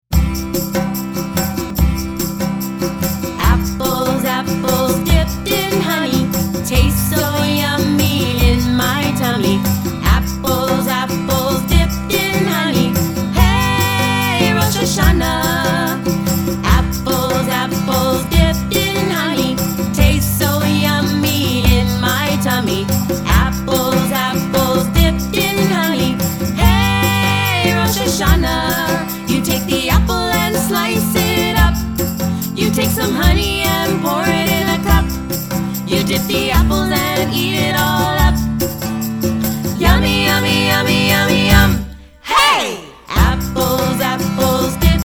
Celebrate Fall with this Autumn Song